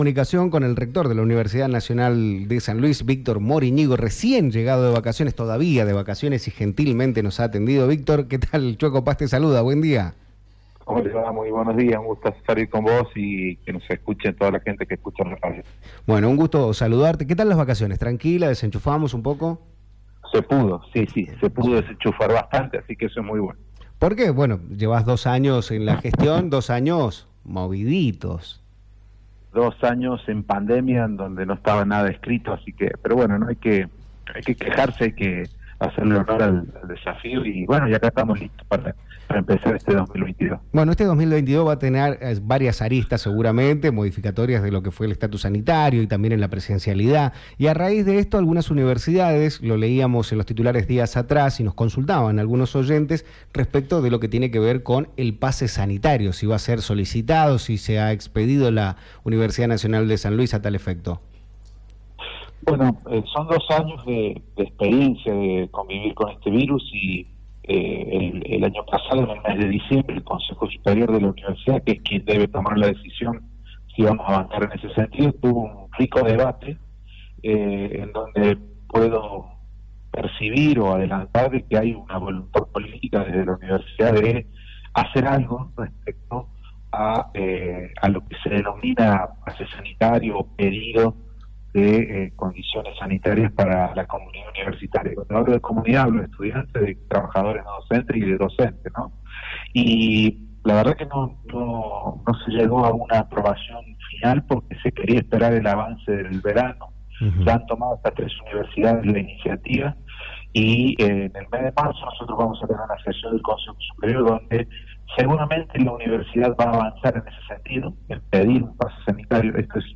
En una entrevista con Víctor Moriñigo, Rector de la Universidad Nacional de San Luis, nos habla sobre como serán las nuevas medidas sanitarias, luego de dos años de pandemia.